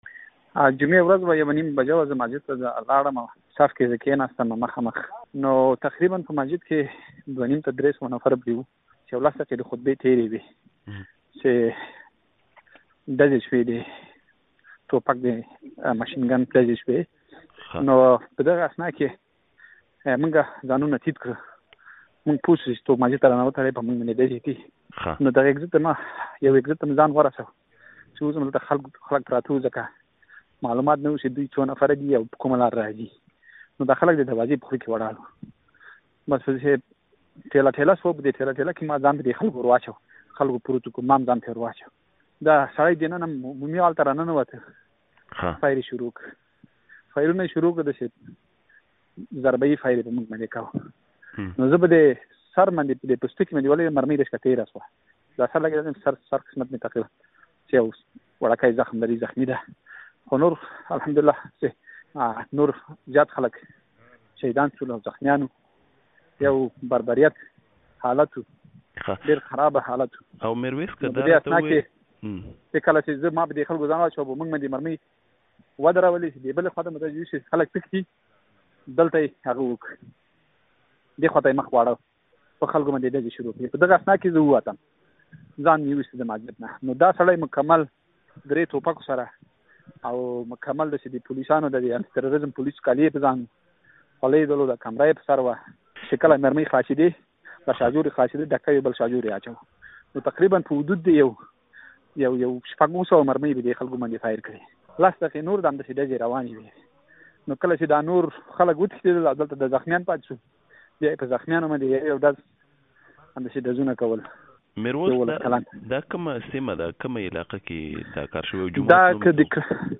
د نیوزیلېنډ په کرایسټچرچ جومات کې ژوبل پښتون د سترګو لیدلی حال وايي